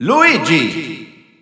Spanish Announcer announcing Luigi.
Category:Luigi (SSBU) Category:Announcer calls (SSBU) You cannot overwrite this file.
Luigi_Spanish_Announcer_SSBU.wav